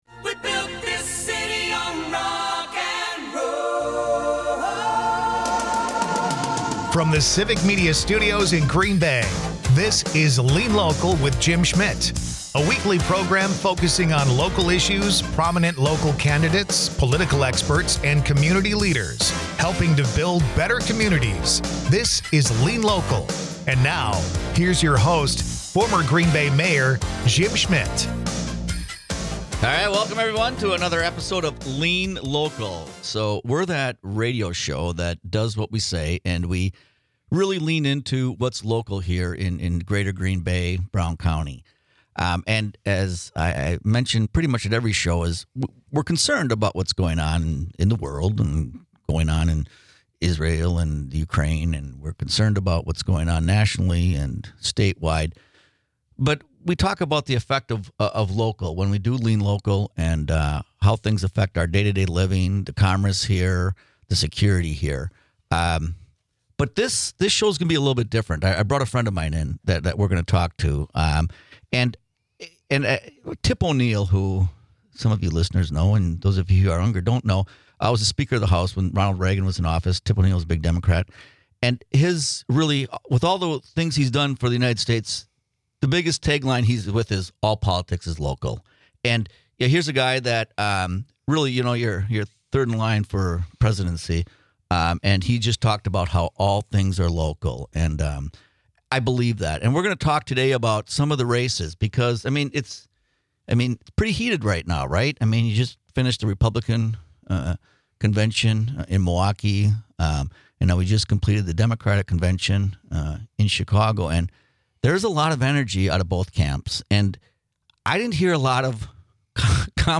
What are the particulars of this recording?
Lean Local is a part of the Civic Media radio network and airs Sundays at 11am on 97.9 WGBW.